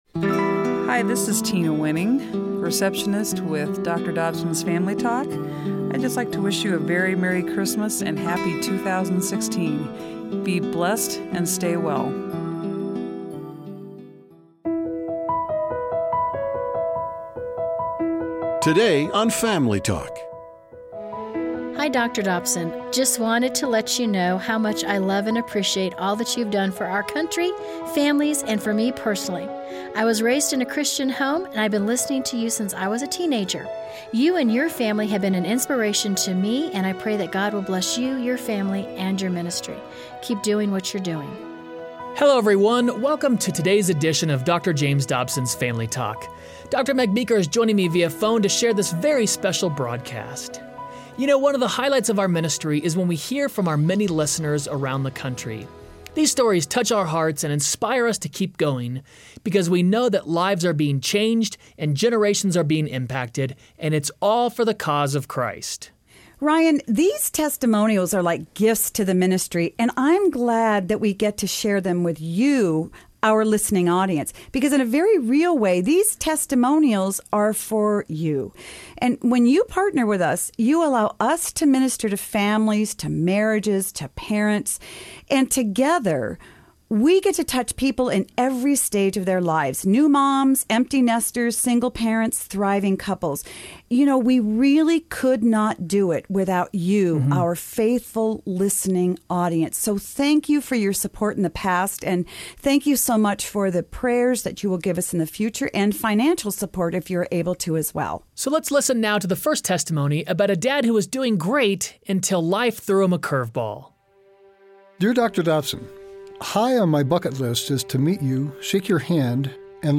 Family Talk listeners call in to share how the ministry has impacted their lives. Hear their inspiring stories on todays edition of Dr. James Dobsons Family Talk.